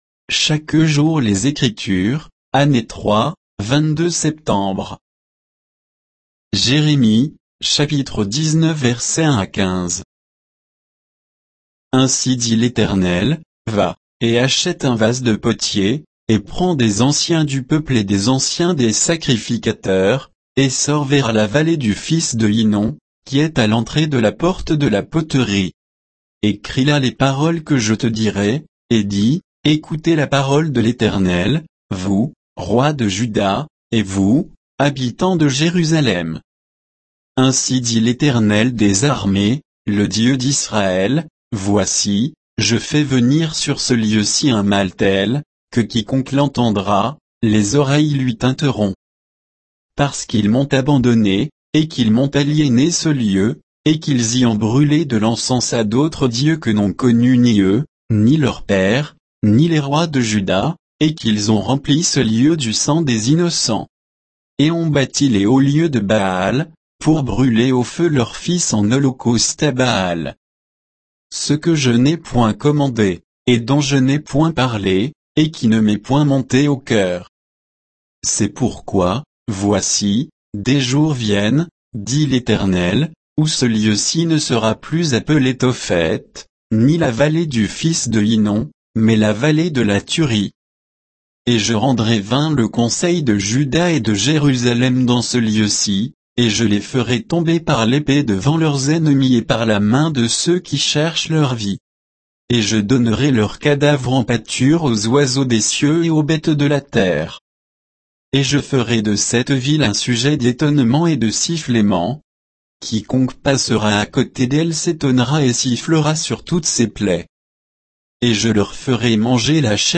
Méditation quoditienne de Chaque jour les Écritures sur Jérémie 19